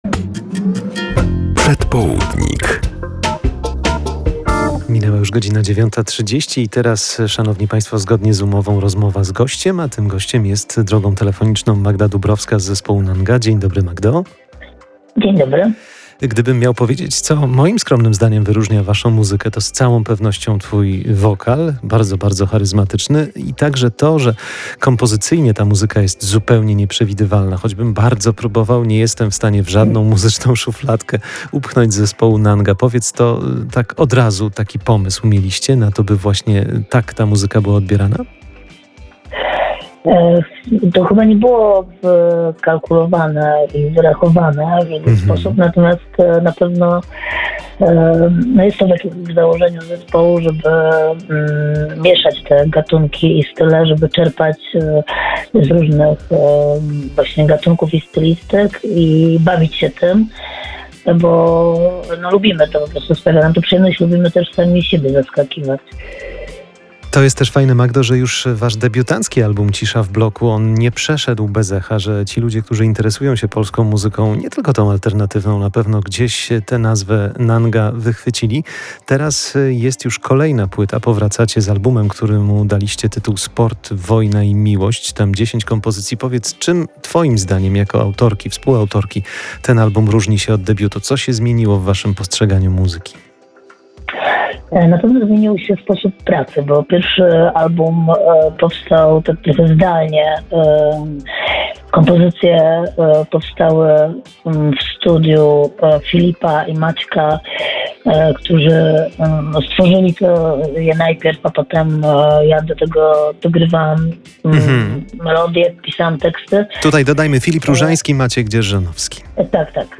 Zespół NANGA zagra w Lublinie [POSŁUCHAJ ROZMOWY]